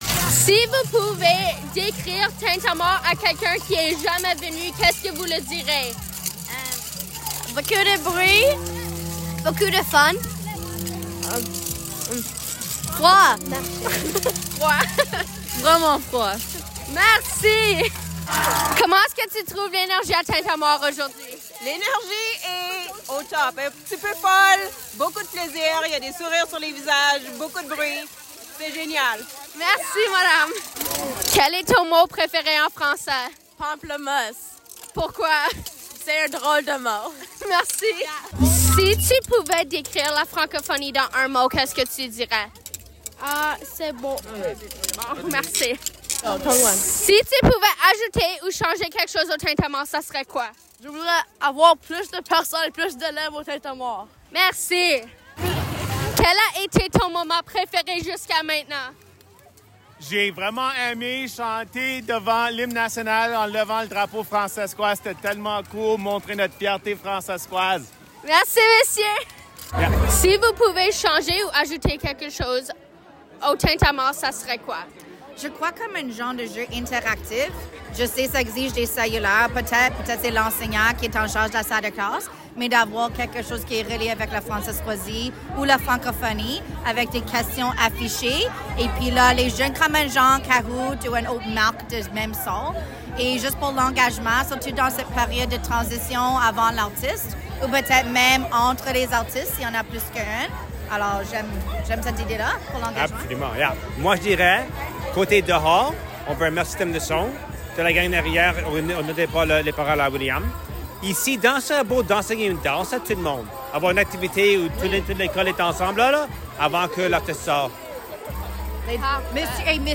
Les Francophones crient leur fierté dans les rues de Prince Albert lors du Tintamarre 2025